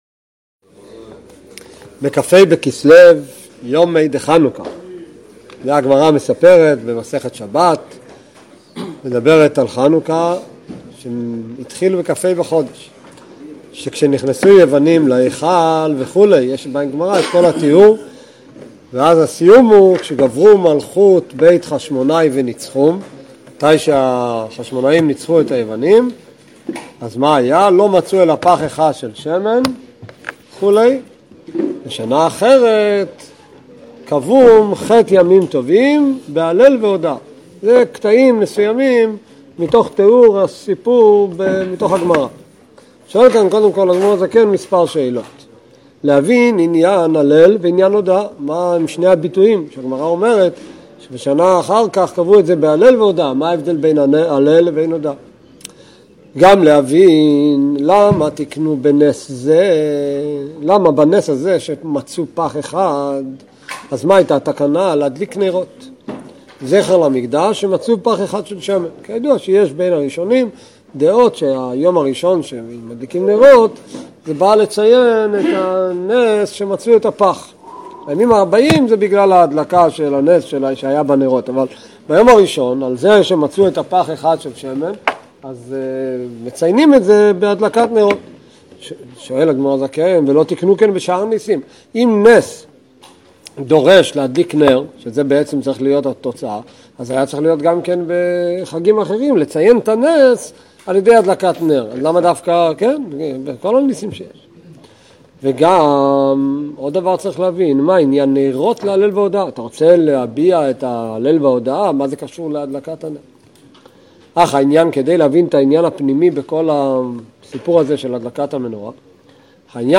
שיעורים בתורה אור